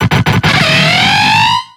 Cri de Yanmega dans Pokémon X et Y.